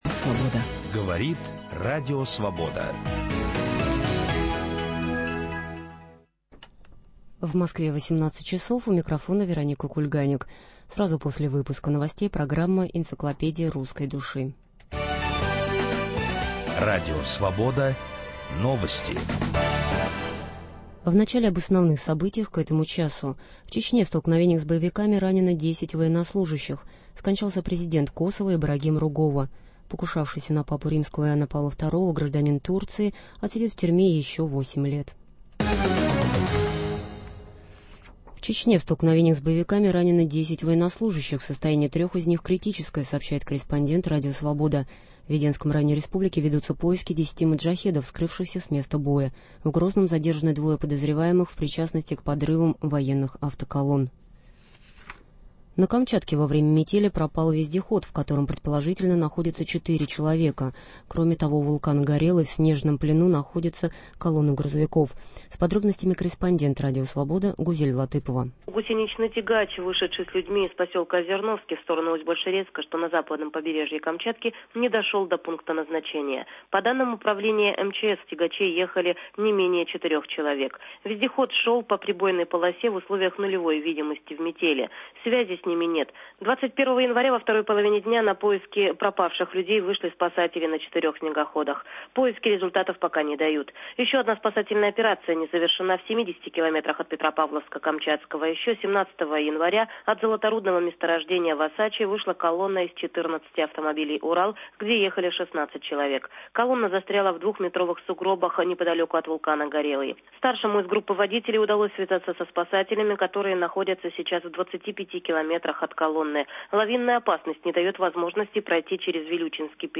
Живой опыт самопознания в прямом эфире с участием слушателей, который ведет писатель Виктор Ерофеев. Это попытка определить наши главные ценности, понять, кто мы такие, о чем мы спорим, как ищем и находим самих себя.